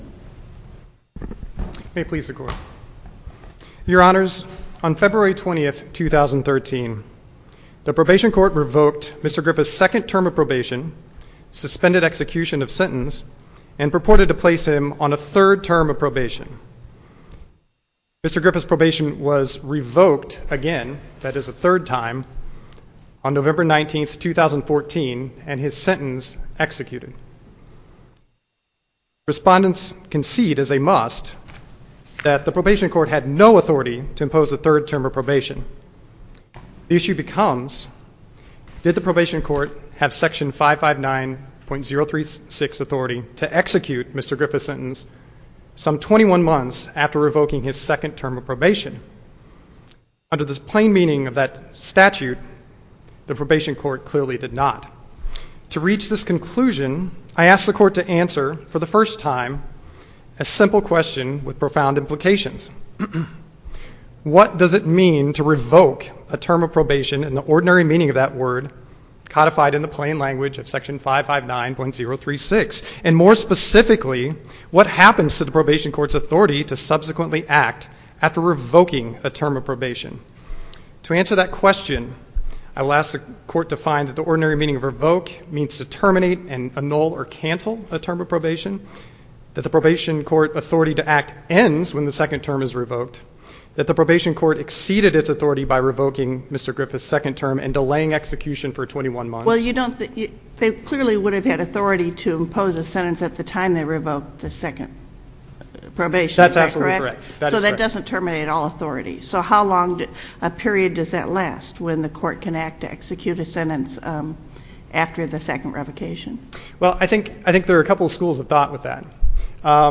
Challenge to revocation of probation and resulting prison sentence Listen to the oral argument: SC97056 MP3 file